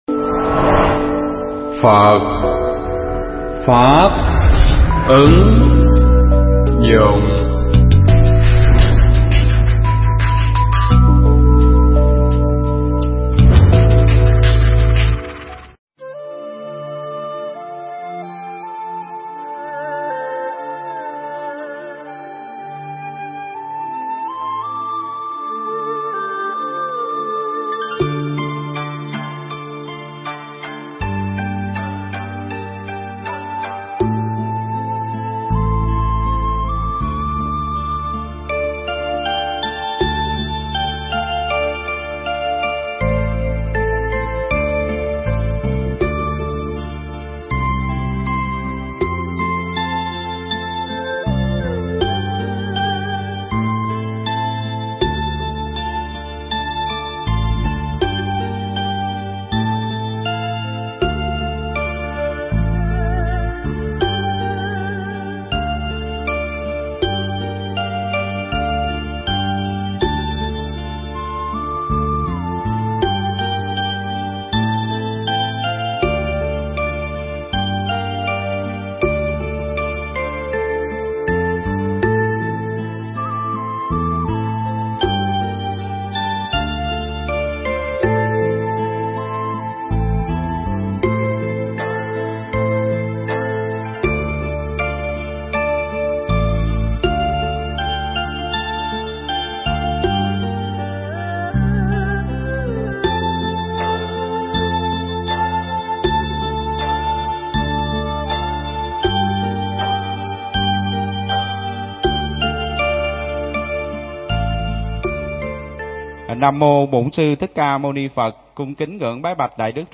Bài giảng Trước Tự Trách Mình